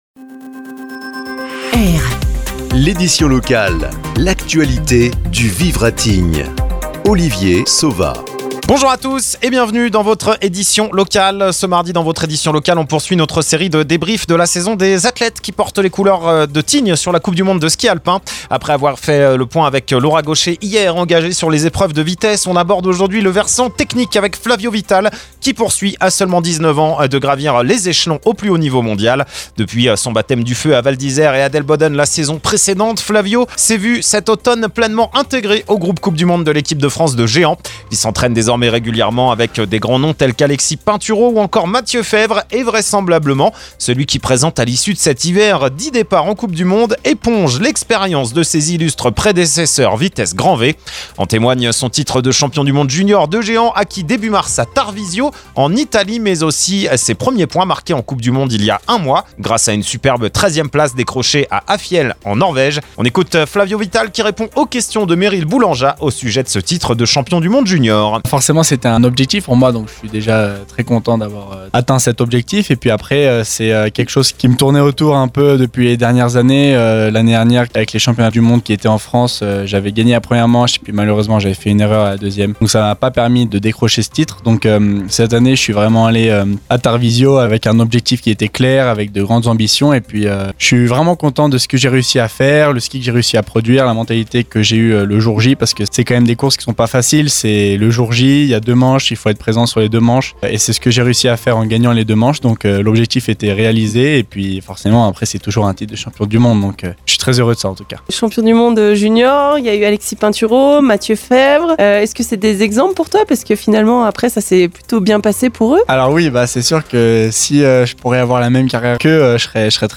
AU SOMMAIRE DE L’ÉDITION LOCALE